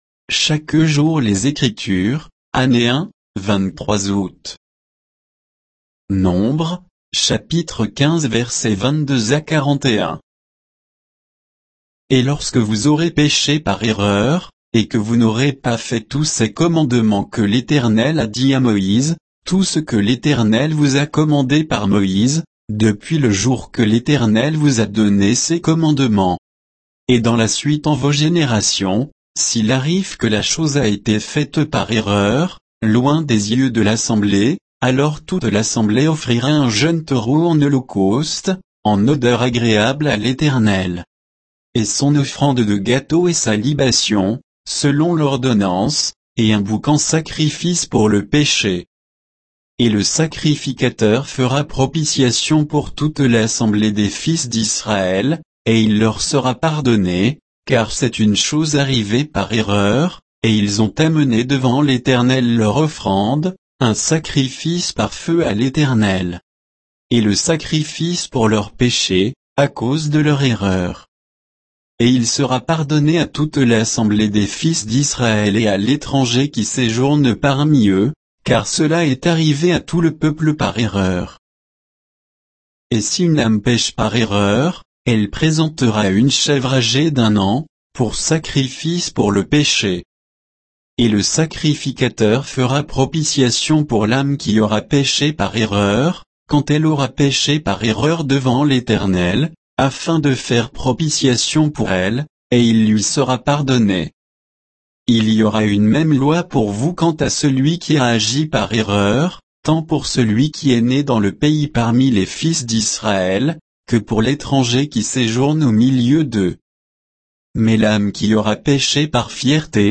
Méditation quoditienne de Chaque jour les Écritures sur Nombres 15